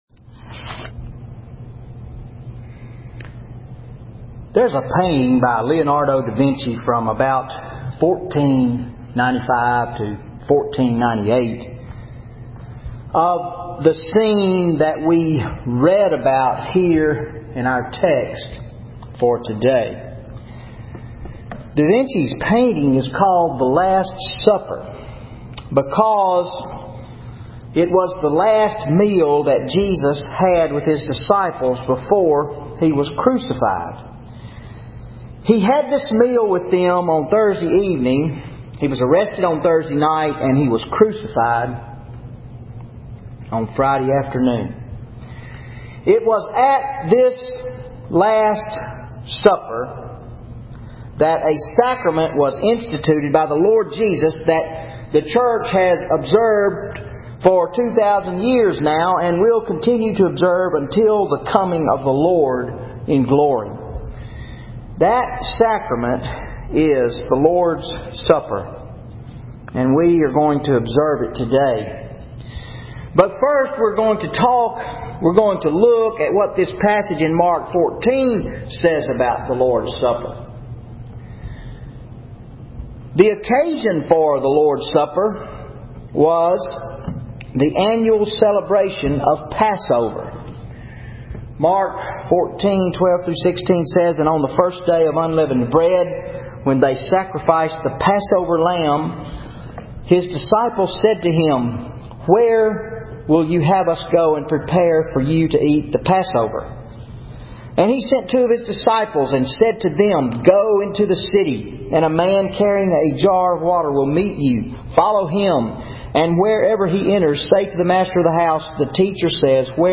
Sermon July 7, 2013 Mark 14:12-26 The Lord’s Supper